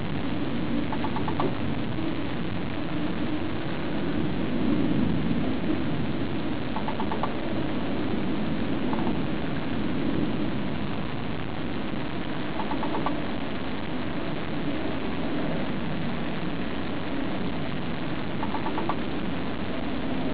Re: [T3] Odd Type 3 sound
A woodpecker in the rain?